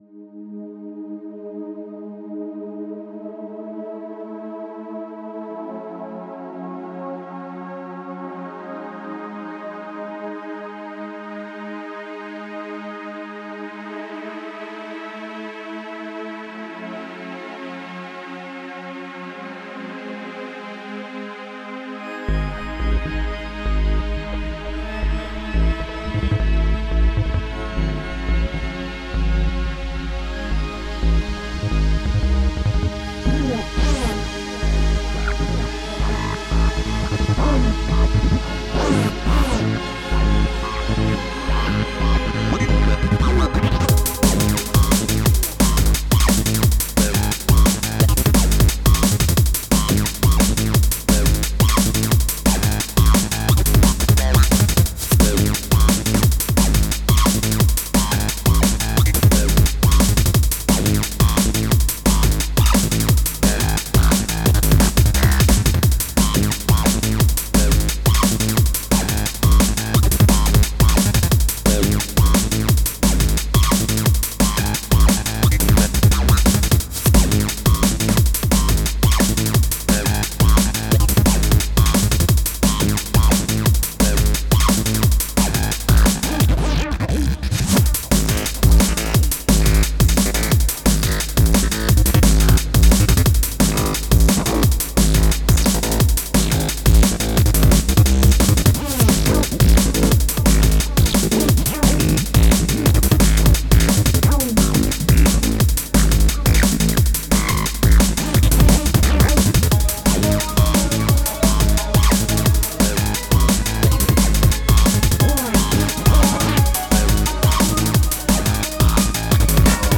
Music / Techno
dnb idm microtonal xenharmonic electronic